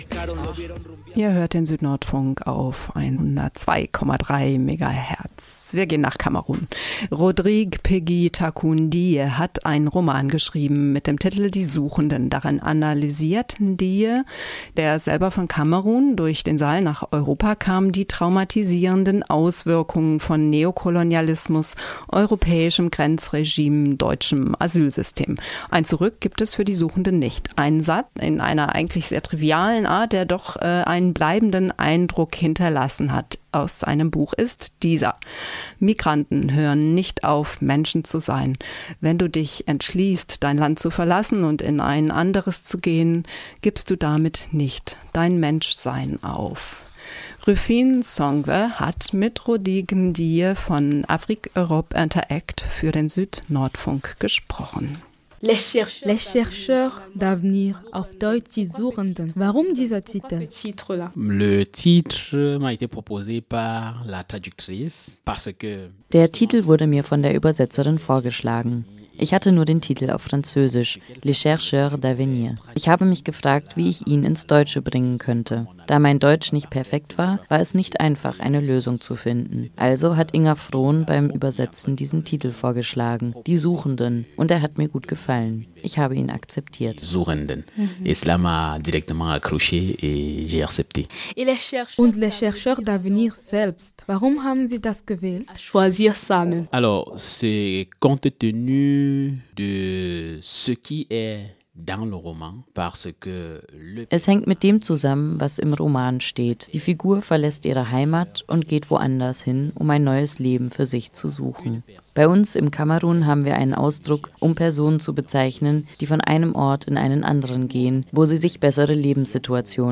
hier aus der Sendung heraus geschnitten)